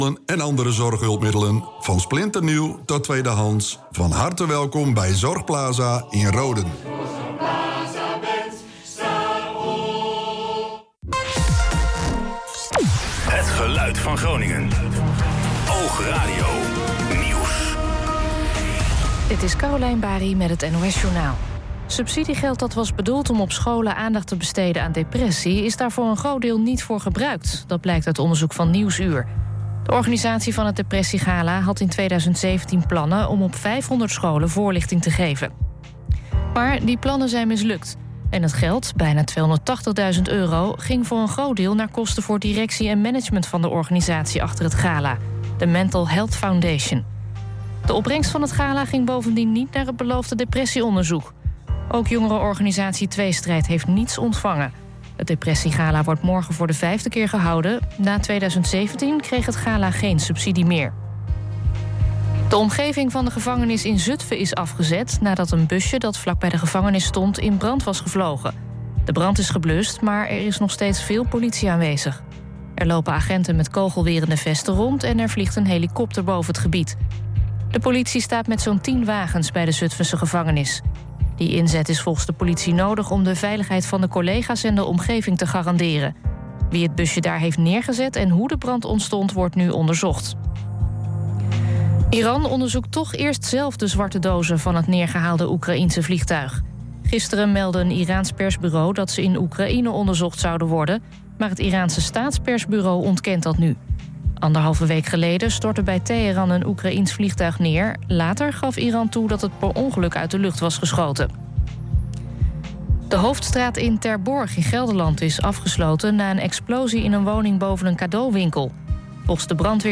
OOG Sport verzorgde zondag een live radioverslag van het voetbalduel tussen Be Quick 1887 en MSC in de hoofdklasse A. De Groningers wonnen met 5-0.